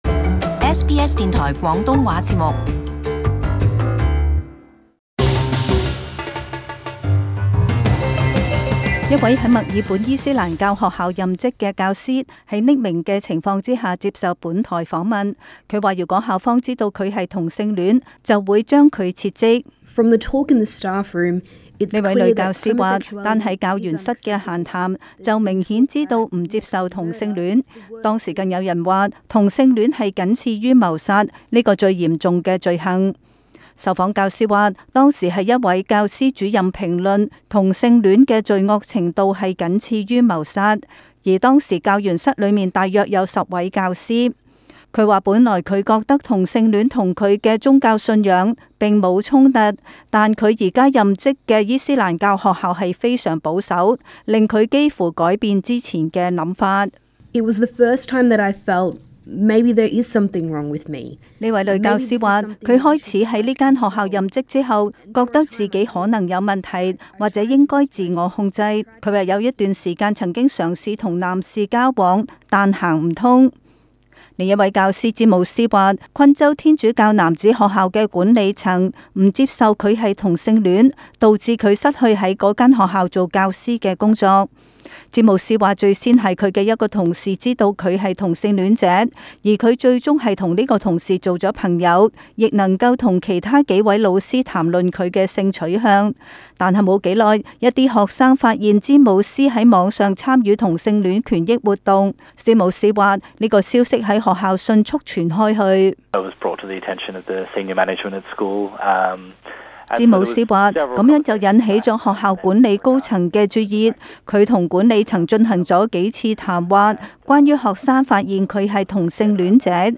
【時事報導】同性戀教師遭宗教學校歧視